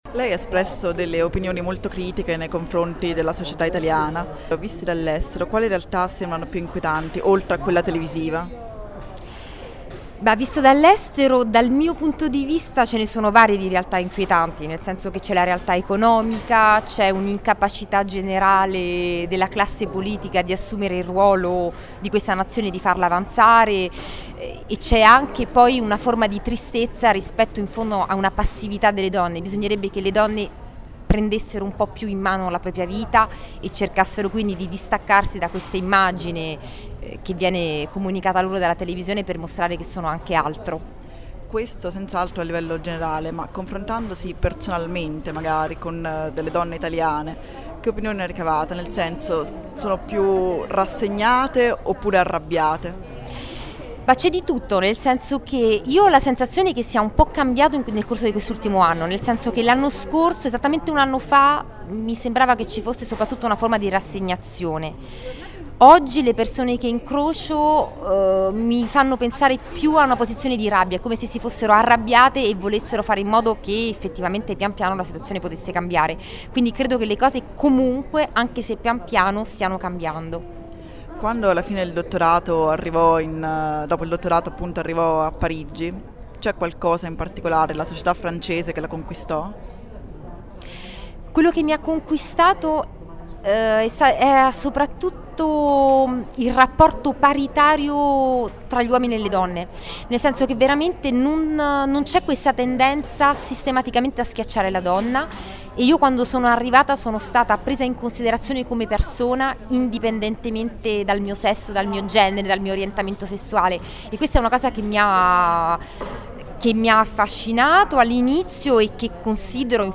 L’intervista: